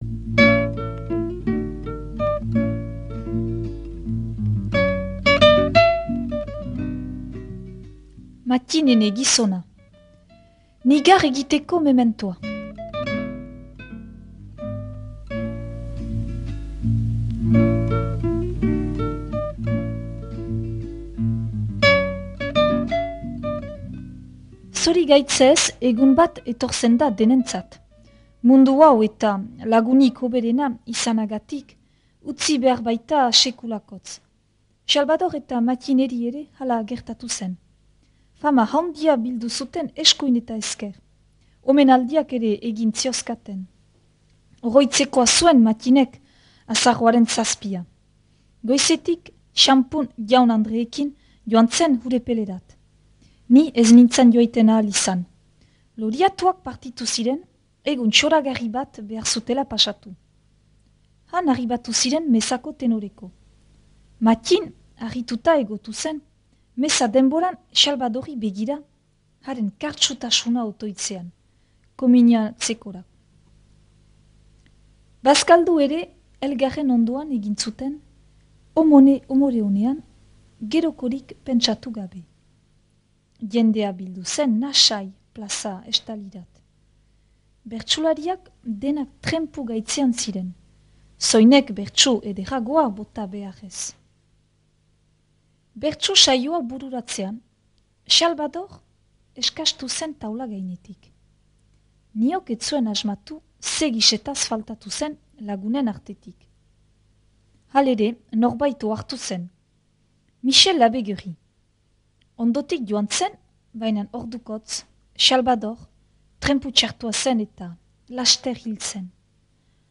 Gure artxiboetarik atera sail hau, Ahetzeko Mattin Trecu (1916-1981) bertsolariari eskainia.
proposatu irakurketa da.